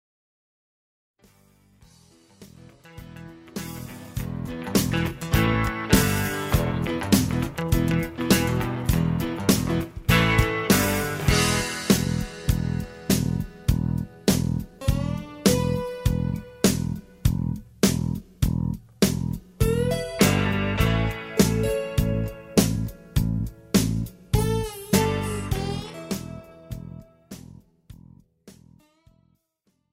Category Country